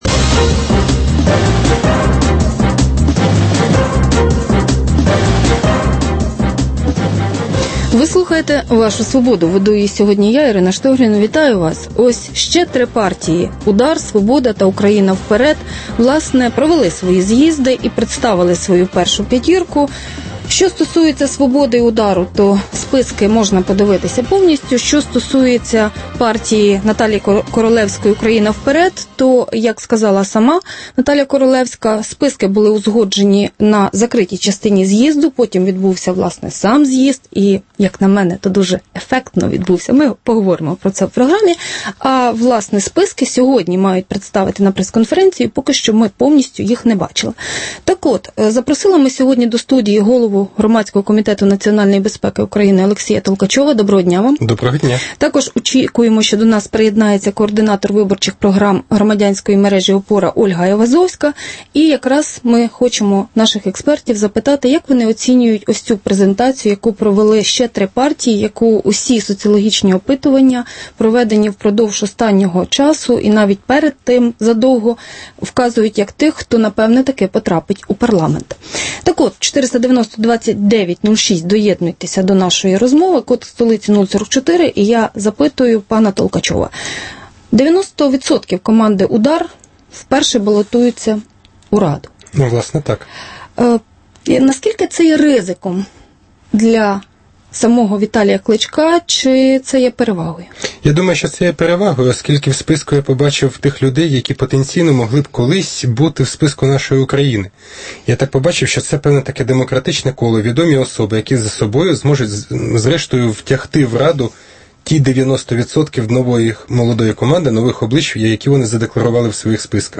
Дискусія про головну подію дня